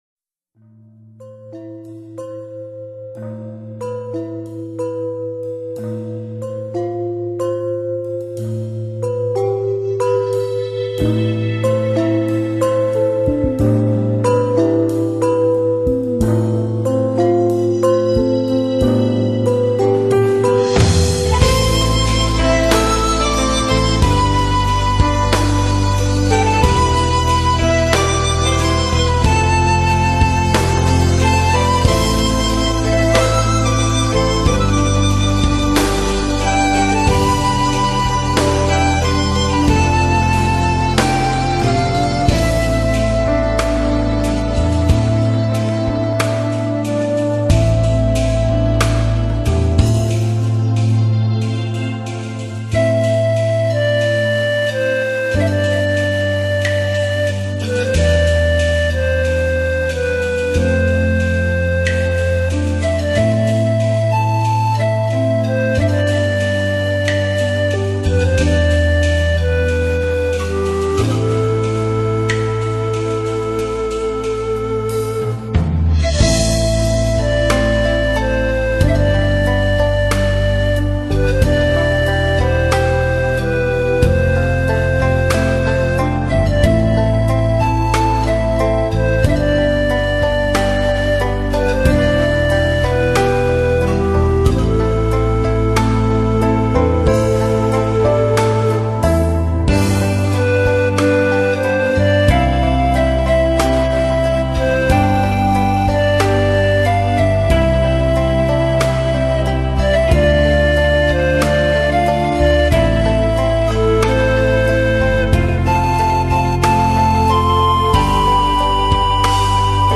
古典民乐, 经典配乐 你是第12123个围观者 1条评论 供稿者： 标签：, ,
清幽的旋律，还在灵魂深处涤荡。